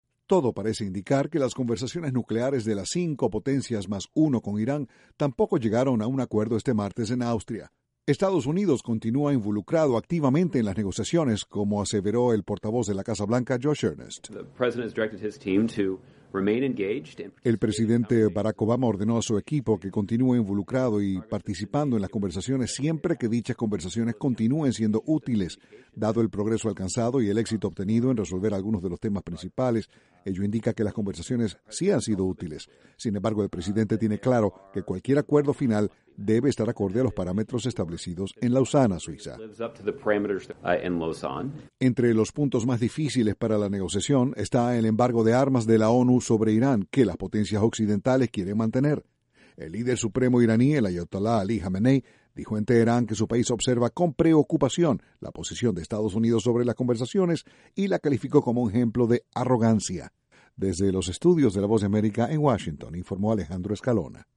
Se pensaba que el P5 mas 1 llegaria a un acuerdo con Iran sobre su programa nuclear este lunes, pero tendra que haber una nueva extension de conversaciones. Desde la Voz de America, Washington, informa